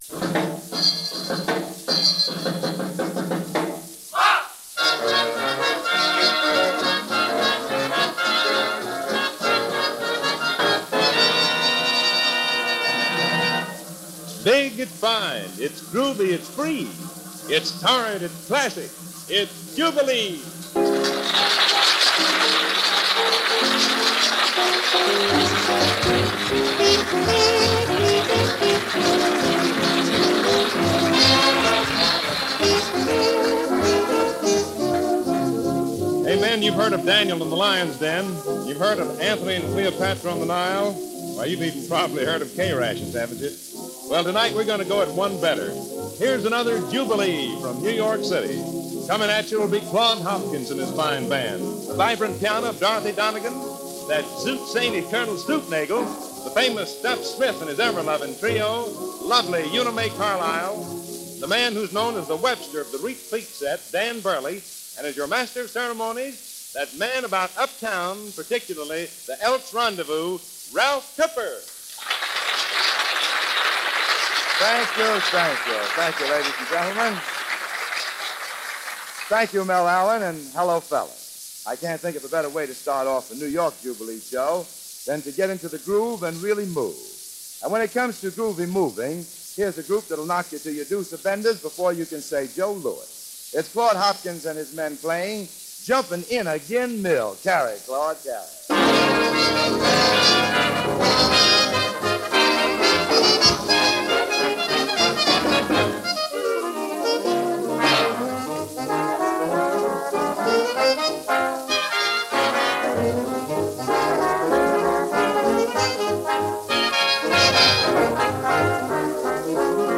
Back over to some Big Band of the 1940s this week.